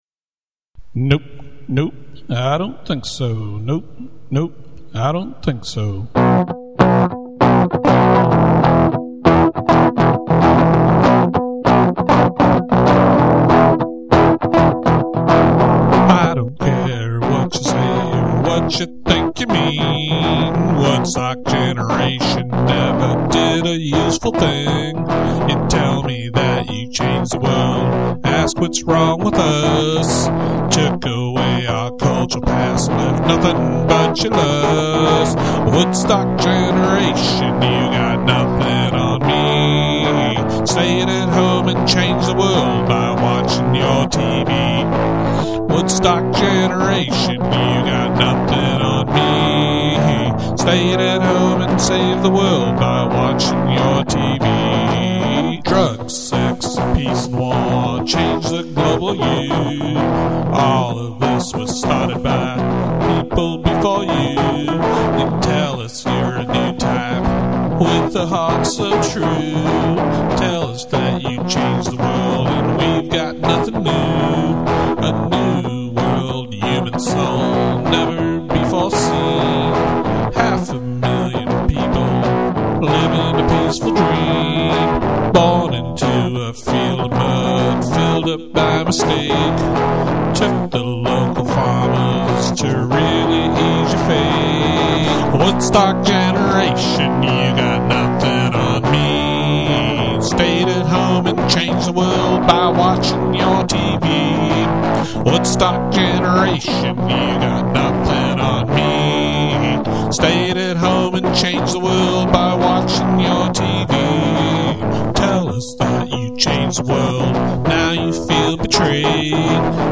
rock song